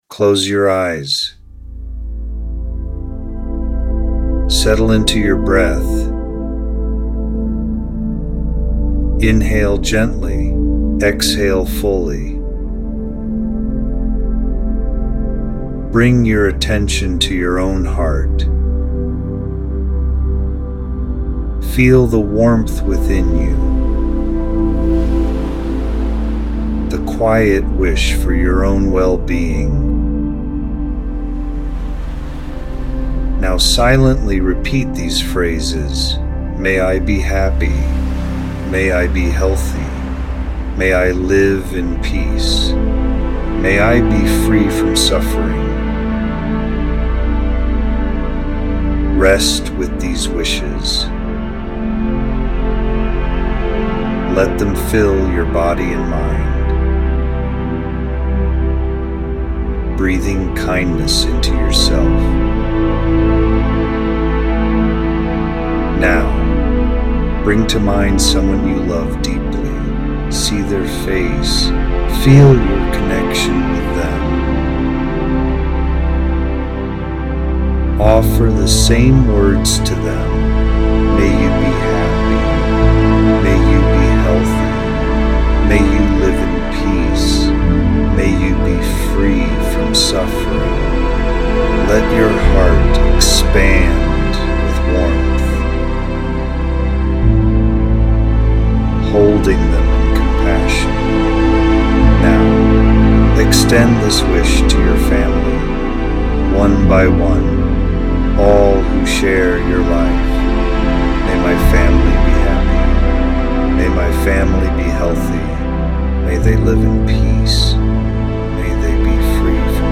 This meditation gently guides you to cultivate compassion — first for yourself, then for others. By repeating phrases of goodwill such as “May I be happy, may I be healthy, may I live with ease,” you gradually extend kindness outward to loved ones, acquaintances, and eventually all beings.